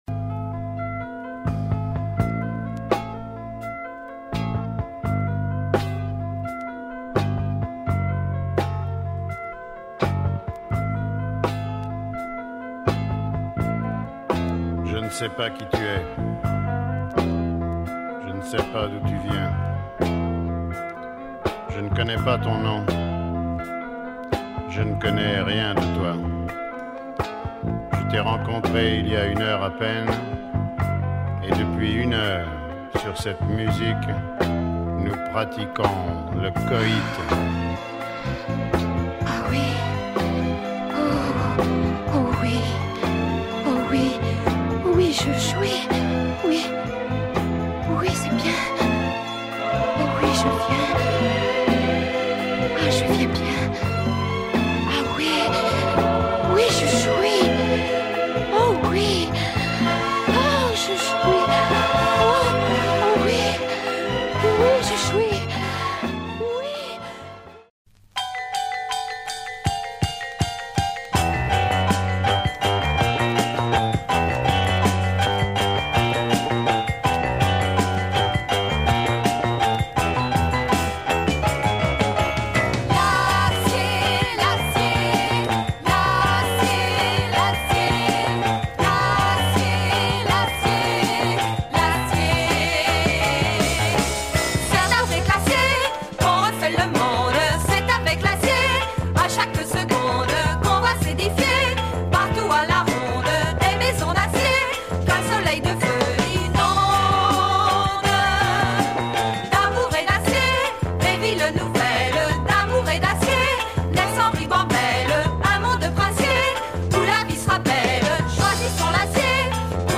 Funny soundtrack
vocal samba